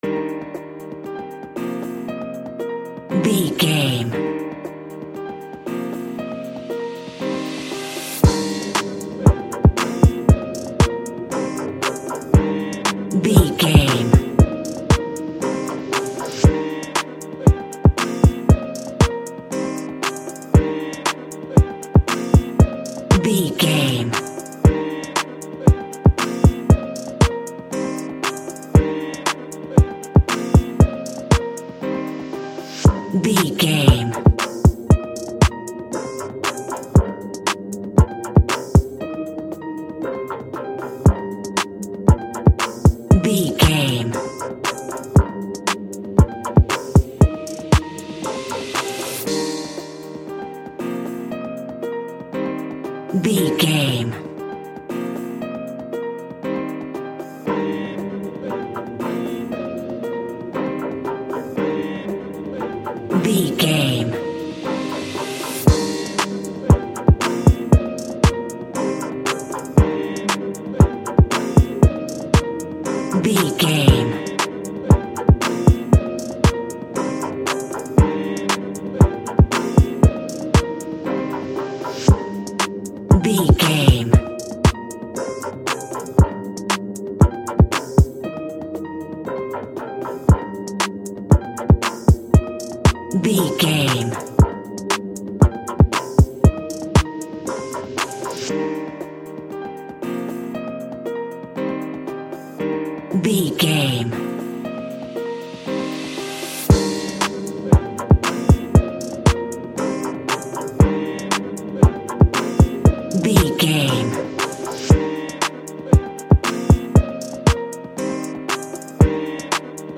Uplifting
Ionian/Major
drums
cheerful/happy
bouncy
energetic